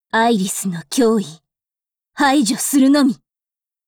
Cv-90202_warcry.wav